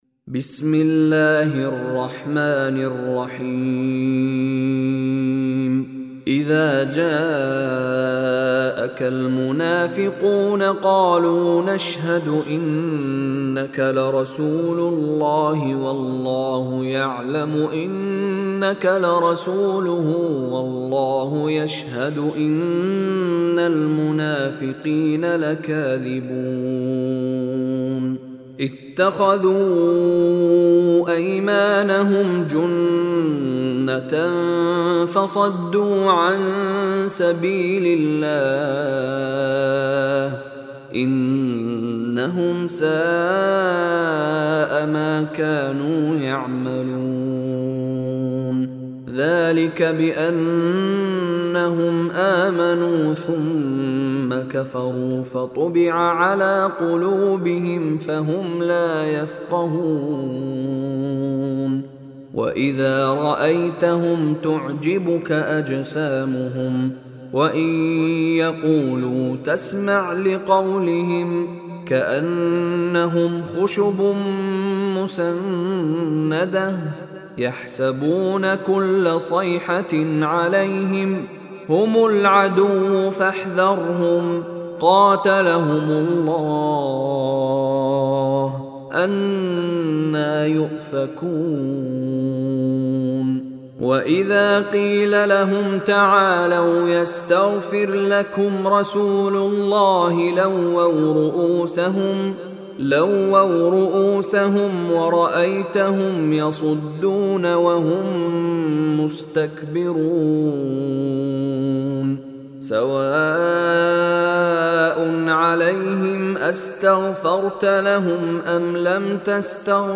Surah Munafiqun Recitation by Mishary Rashid
Surah Munafiqun listen online mp3 recitation in Arabic. Download Surah Munafiqun audio mp3 in the voice of Sheikh Mishary Rashid Alafasy.